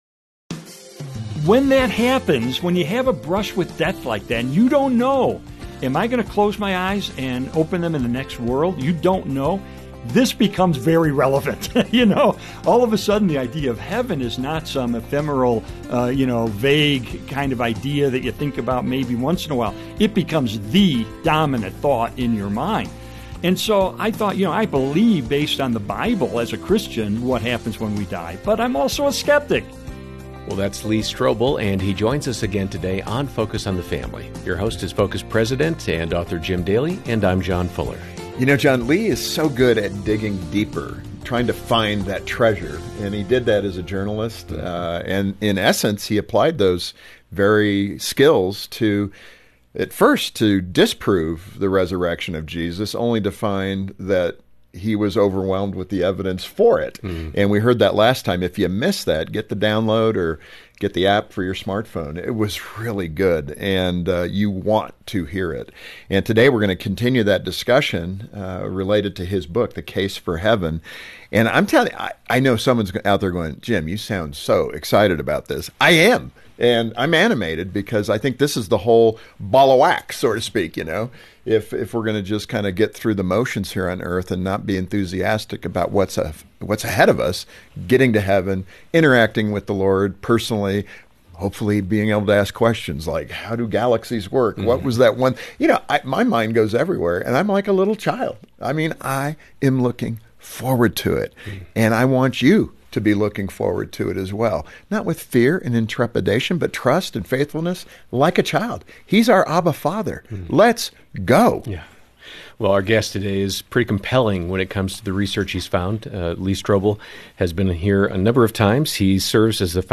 It’s a hope-filled conversation that will give you a glimpse of the other side.